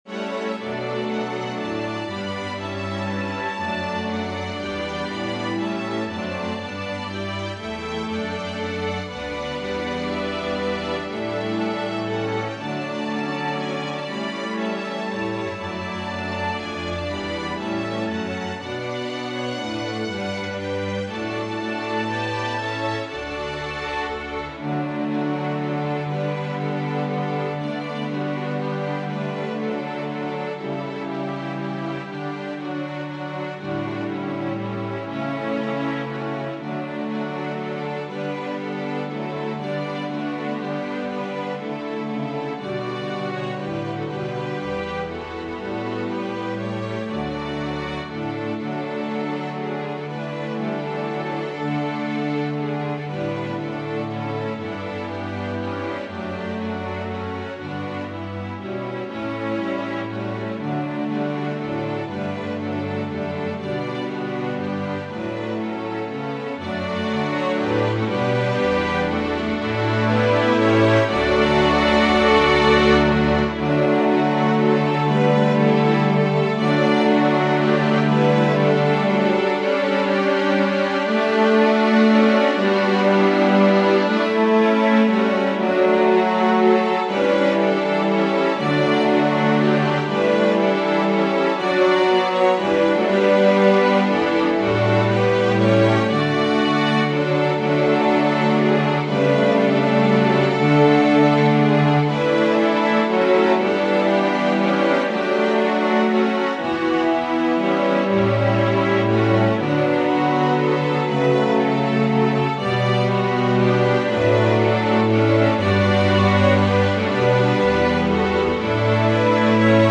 Organ/Organ Accompaniment